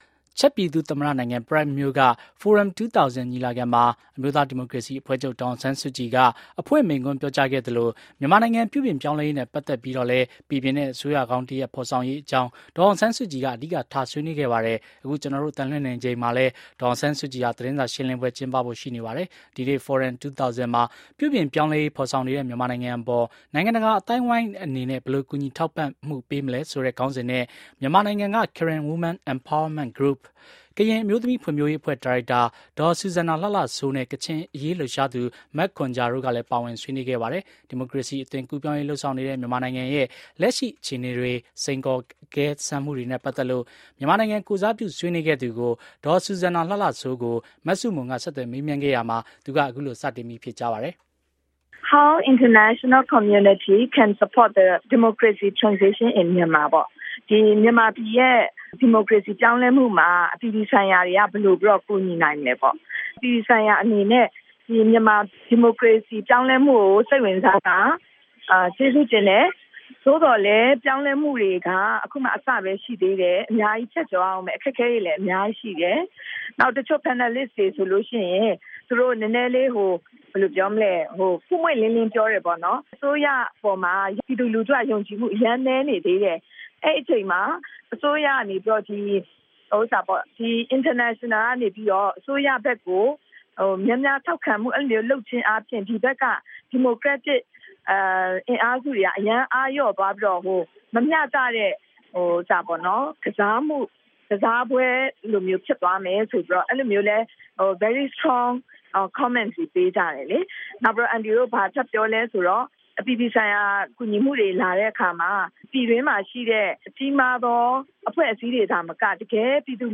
ဆူဇန်နာ လှလှစိုးနဲ့ အင်တာဗျူး